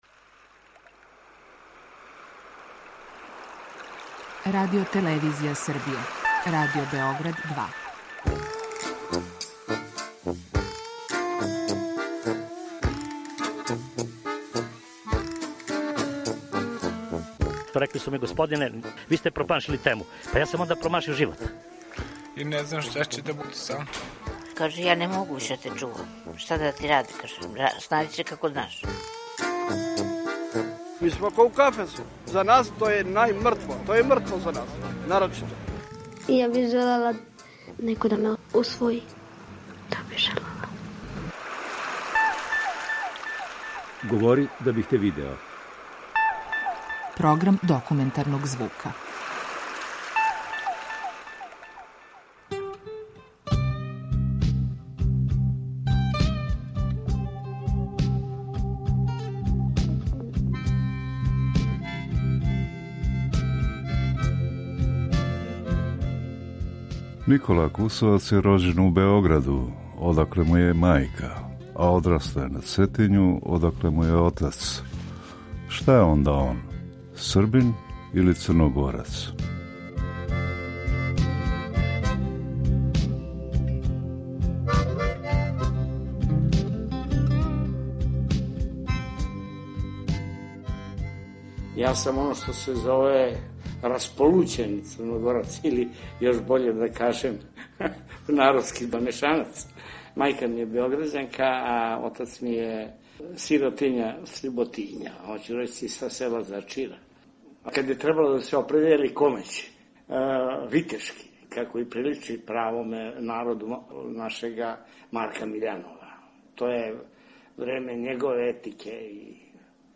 Документарни програм
Серија полусатних документарних репортажа